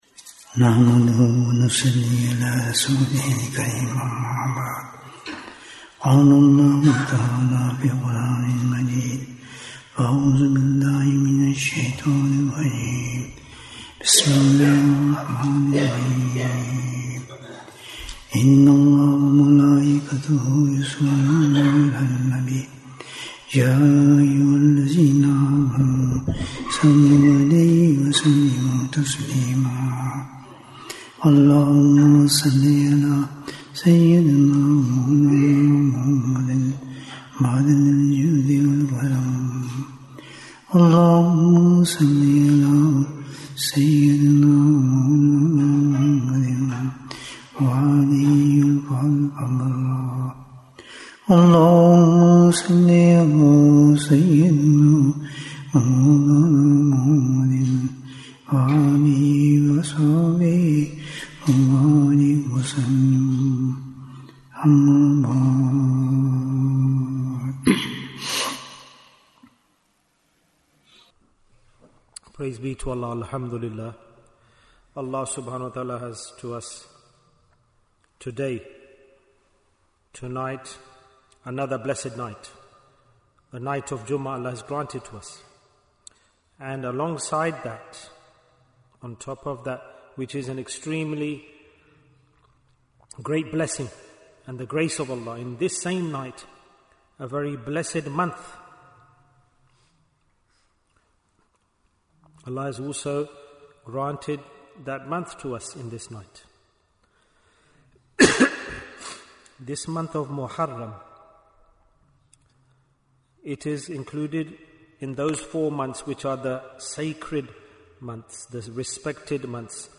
The Message of Ashoorah Bayan, 28 minutes26th June, 2025